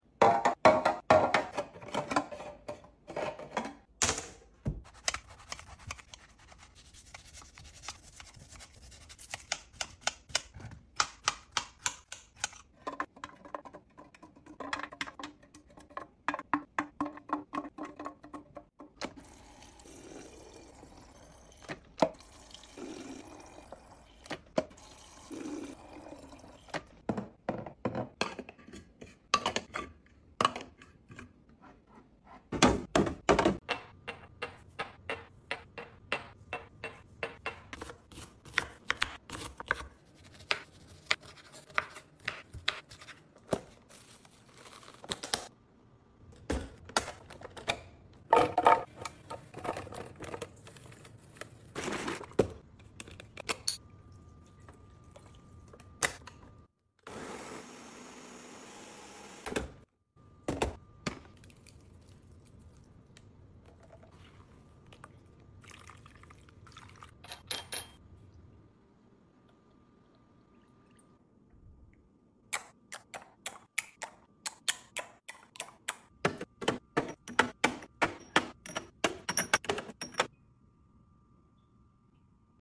drink restock asmr | find sound effects free download